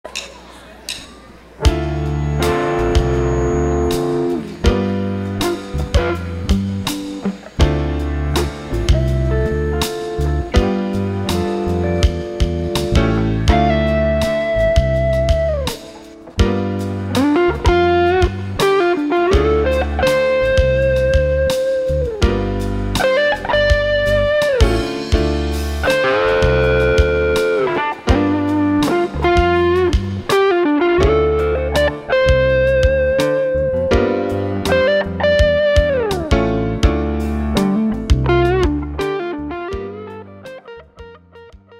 the cusack screamer is a "clean" overdrive with about twice the gain of a typical tube screamer. it offers much more clarity than a typical overdrive. you can actually hear the individual notes of complex chords that would normally be buried.
a '59 historic les paul with burstbuckers through a cusack screamer, into a princeton reverb.